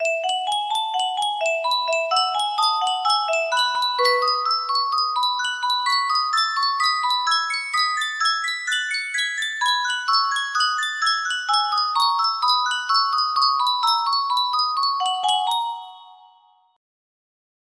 Yunsheng Custom Tune Music Box - Bach Invention No. 1 music box melody
Full range 60